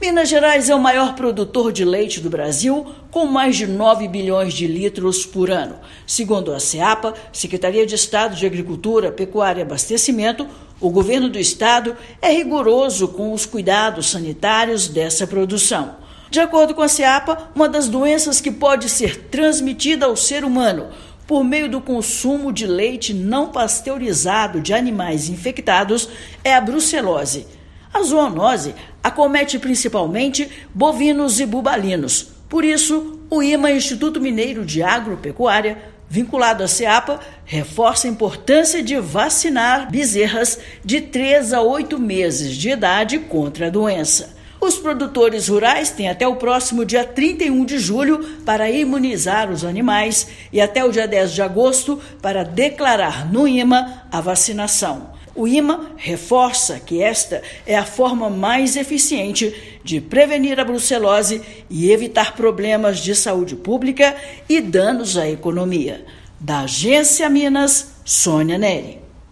Medidas como a vacinação contra brucelose garantem que o leite e seus derivados, como os queijos artesanais, tenham mais segurança alimentar. Ouça matéria de rádio.